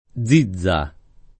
Zizza [ +z&zz a ]